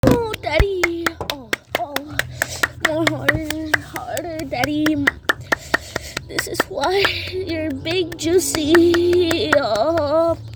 Sus Claping Bouton sonore
Reactions Soundboard2 views